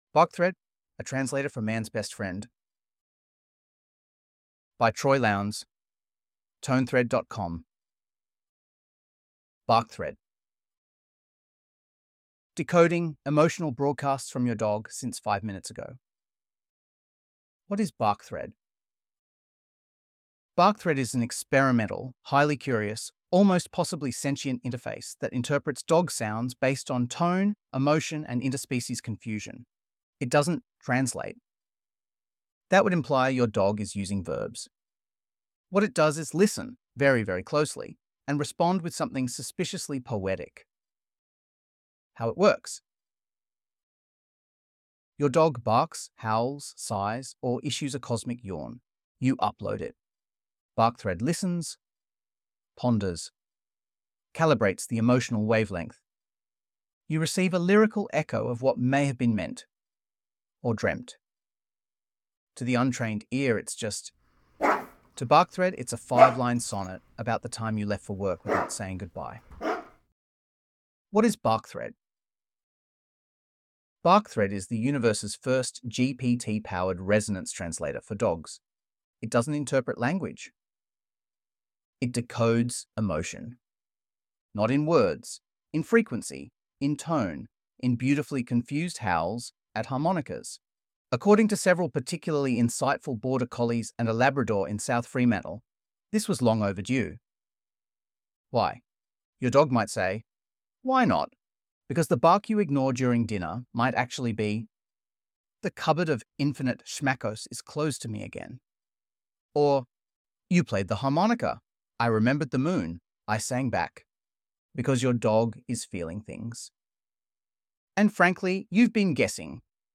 here to listen to Ai narration of the text below.